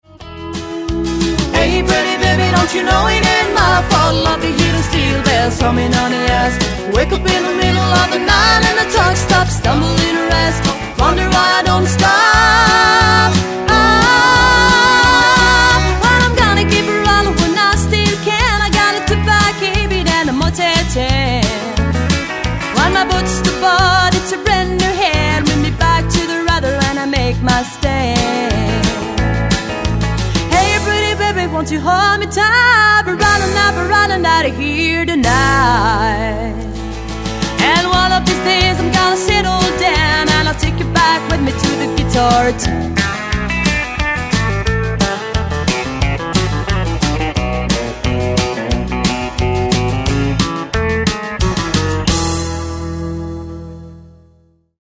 Chant, Harmonies
Pedal Steel Guitare
Guitare Acoustique & Electrique
Basse
Batterie